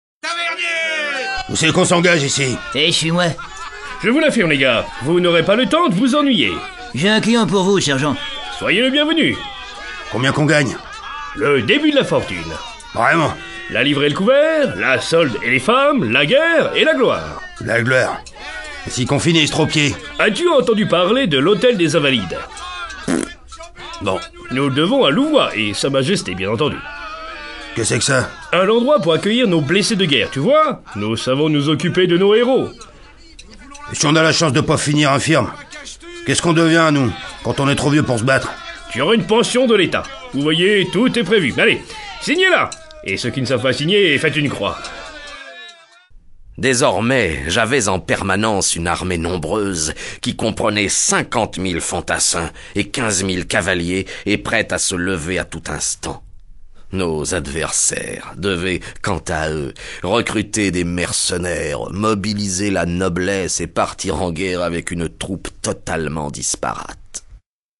Au-delà de l’histoire de sa vie et de son règne, nous vous emmenons dans une épopée, digne d’un flamboyant roman : celle de Louis XIV. Les dialogues sont imaginaires, tous les faits historiquement exacts. Musiques : Lully, Rameau, Haendel, Marin Marais